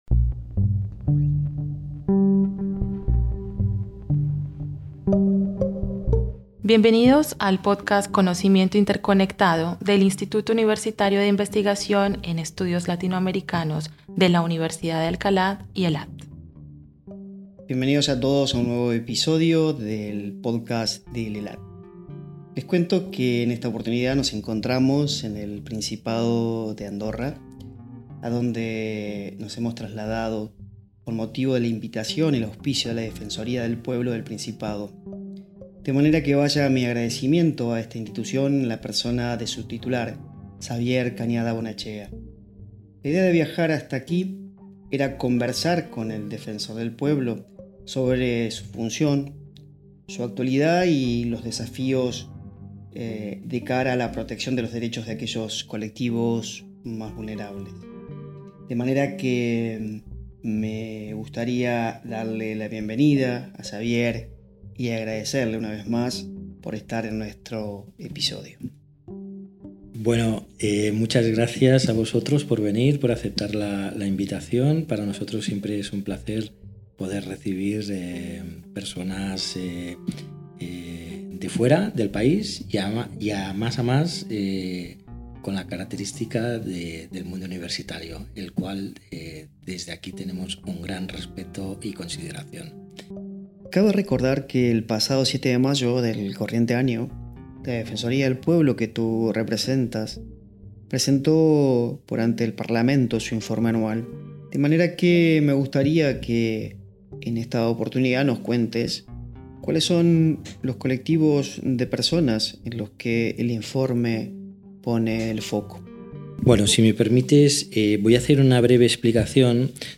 Invitado: Xavier Cañada Bonaetxea (Defensor del Pueblo del Principado de Andorra).